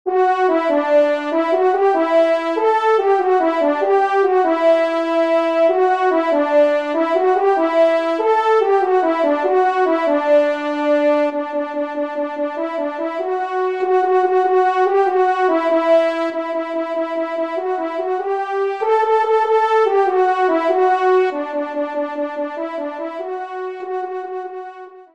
1e Trompe